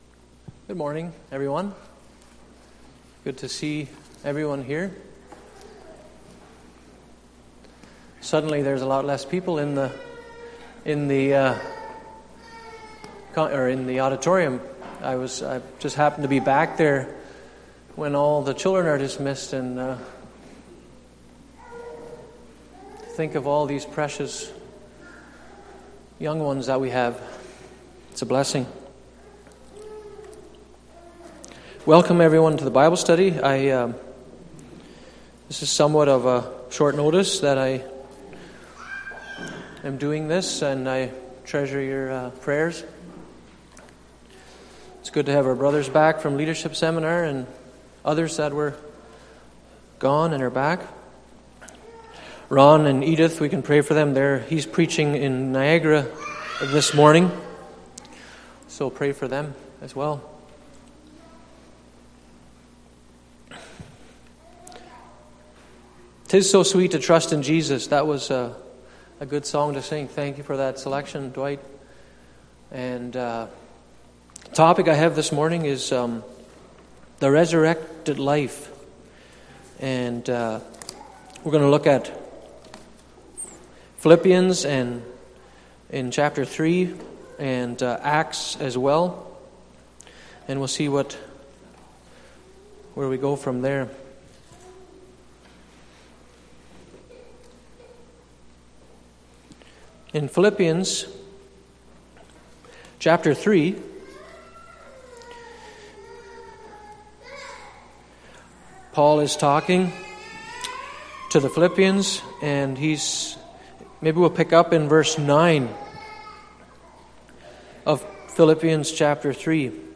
Series: Sunday Morning Bible Study Service Type: Sunday Morning %todo_render% « The Role of the Son